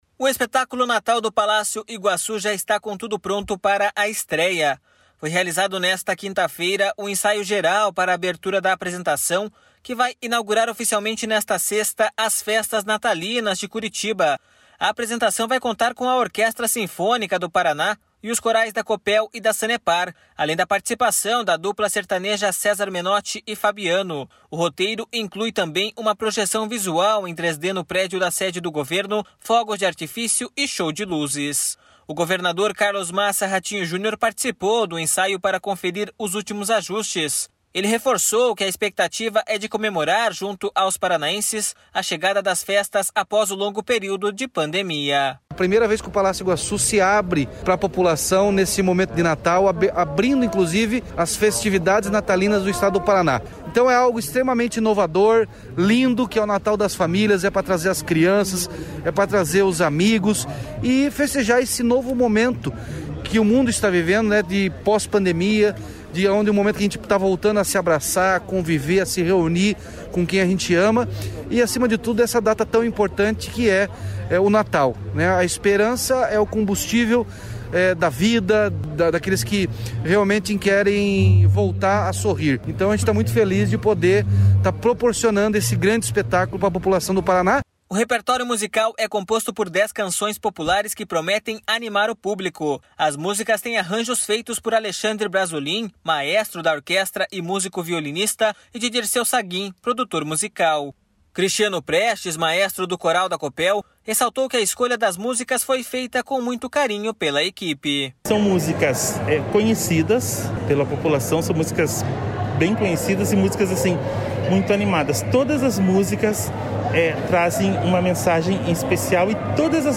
Ele reforçou que a expectativa é de comemorar, junto aos paranaenses, a chegada das festas após o longo período de pandemia.// SONORA RATINHO JUNIOR.// O repertório musical é composto por dez canções populares que prometem animar o público.
ENSAIONATALPALACIO.mp3